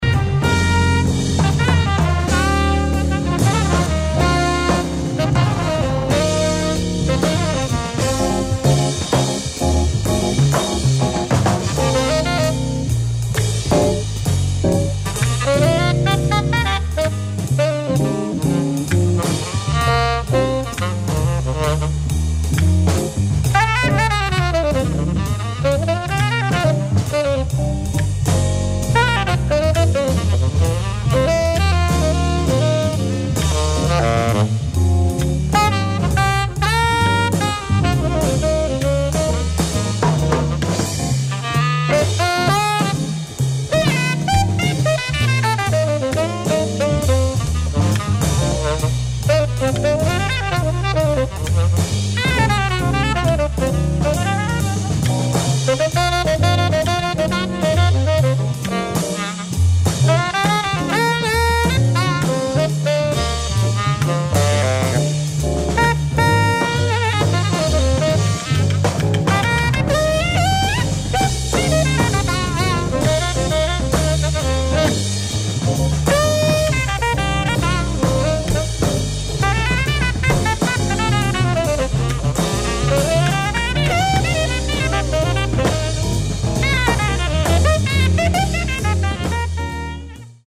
ライブ・アット・シャトーヴァロン・ジャズ祭、フランス 08/23/1972
※試聴用に実際より音質を落としています。